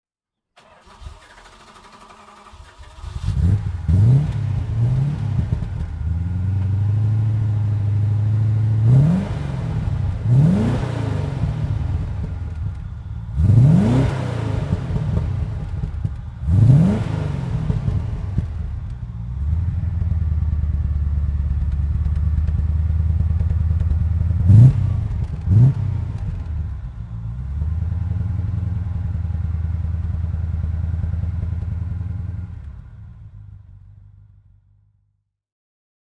Aston Martin V8 Volante Vantage Spec (1981) - Start und Leerlauf
Aston_Martin_Volante_Vantage_1981.mp3